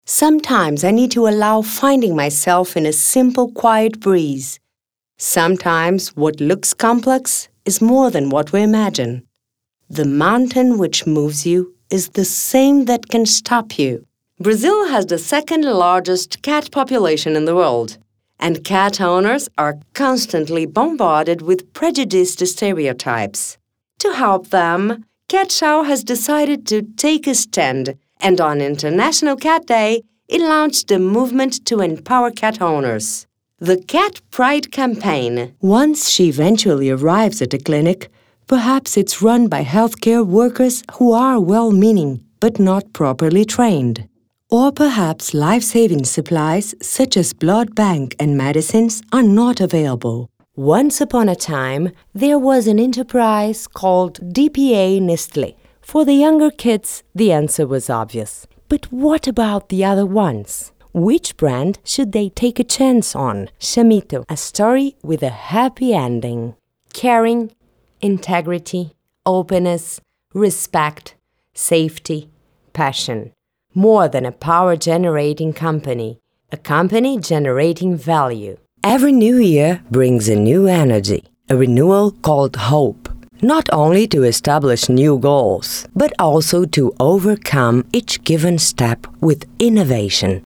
Feminino
Locução Institucional